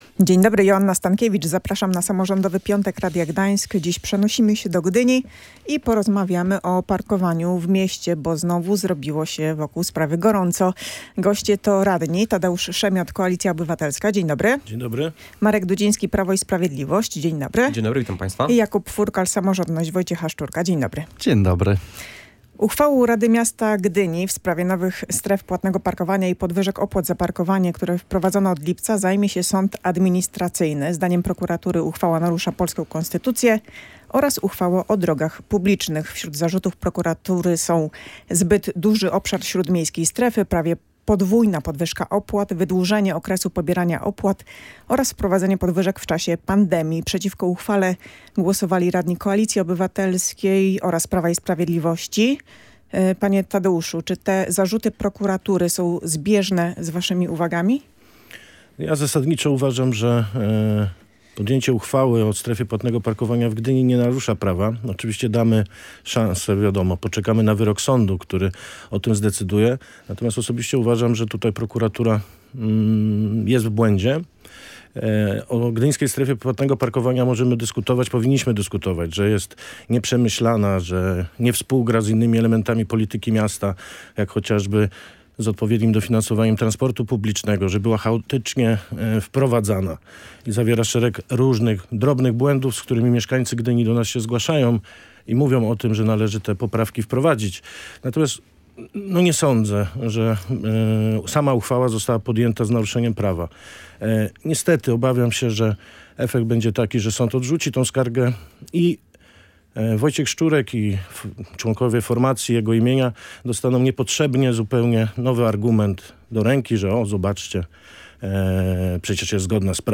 O konsekwencjach wprowadzenia uchwały w „Samorządowym Piątku” rozmawiali radni: Tadeusz Szemiot (Koalicja Obywatelska), Marek Dudziński (Prawo i Sprawiedliwość) oraz Jakub Furkal (Samorządność Wojciecha Szczurka).